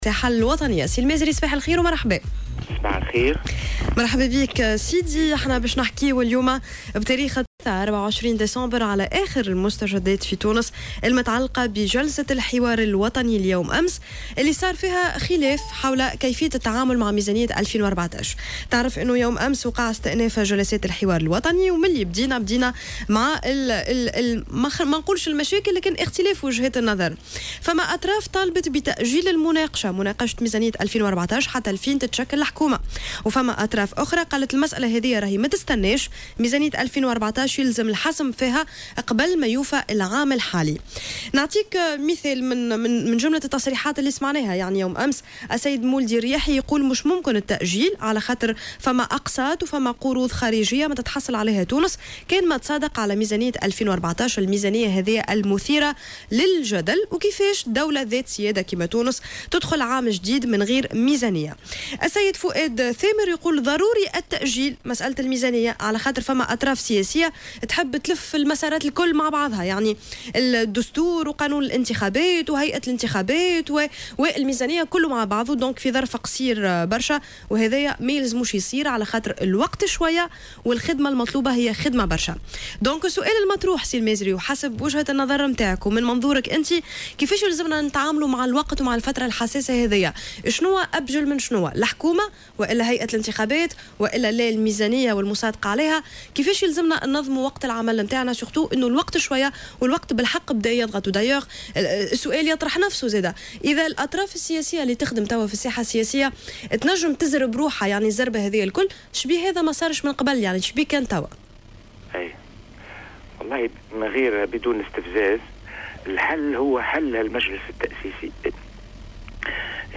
اعتبر السفير السابق لدى اليونسكو،المازري الحداد في برنامج "صباح الورد" على "جوهرة أف أم" المجلس الوطني التأسيسي مصدر الخراب،داعيا إلى ضرورة حلّه ووقف نشاطه.